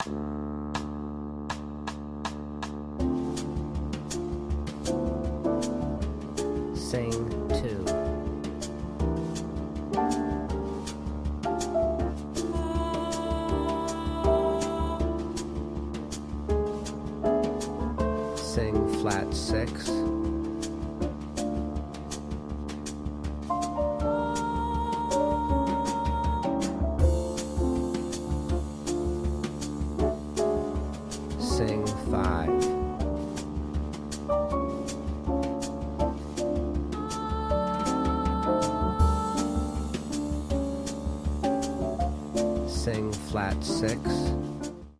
• Singing Exercise with Drone and Alto Voice